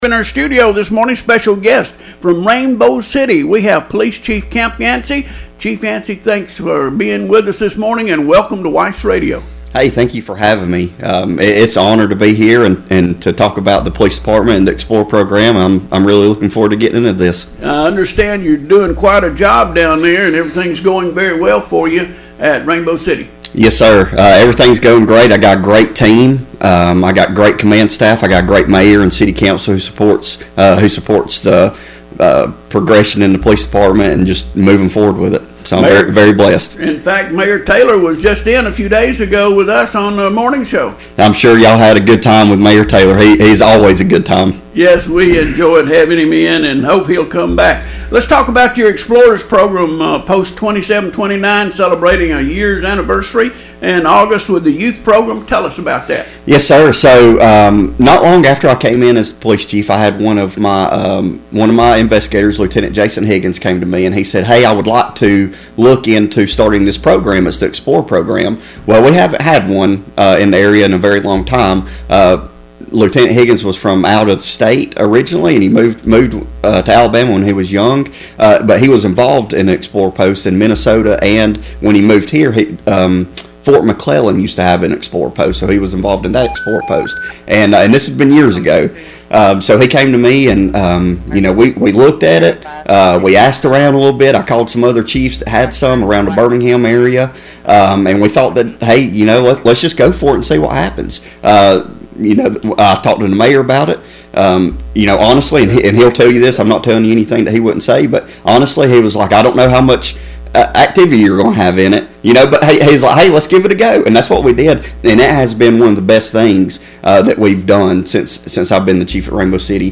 Rainbow City- Rainbow City Police Chief Camp Yancey recently visited WEIS Radio to share the achievements and future plans of the department’s Police Explorer Program, which is approaching its one-year anniversary in August 2025. Explorer Post 2729 has quickly become a standout initiative, emphasizing leadership development, civic responsibility, and public service among local youth. You can listen to the interview here:
Camp-Yancey-RBC-Police-Chief-on-air-72425.mp3